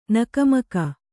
♪ nakamaka